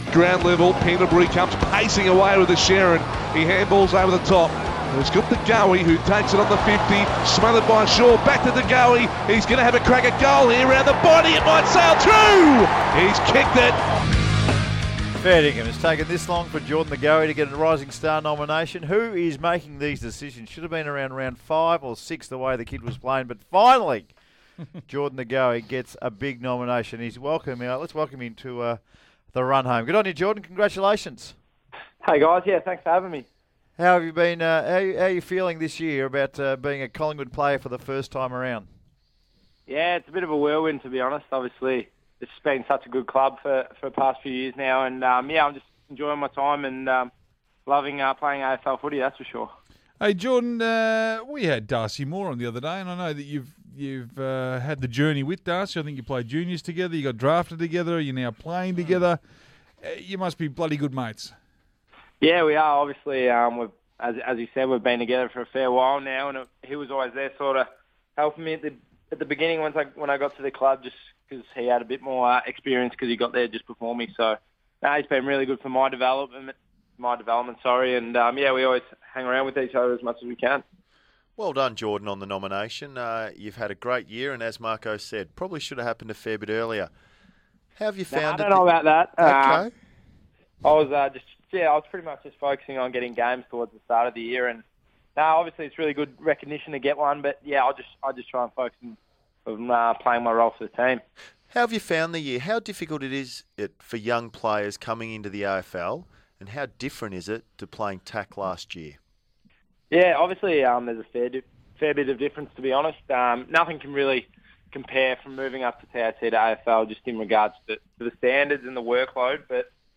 Listen to Jordan De Goey as he joins The Run Home on SEN 1116 on Wednesday 19 August.